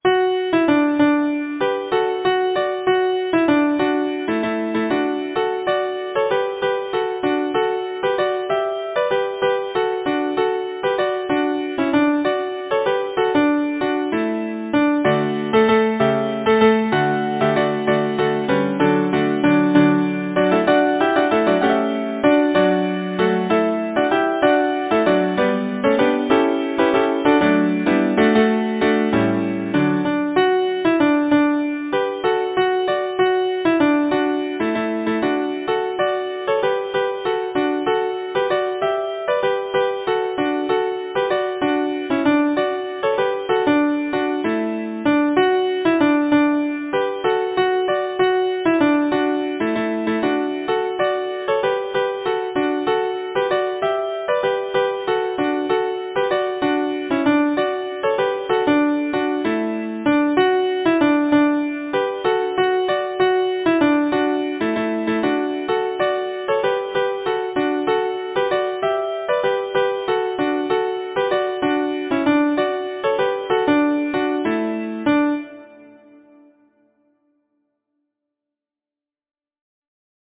Title: Cam’ ye by Athol Composer: Niel Gow Arranger: Henry A. Lambeth Lyricist: James Hogg Number of voices: 4vv Voicing: SATB Genre: Secular, Partsong
Language: Lowland Scots Instruments: A cappella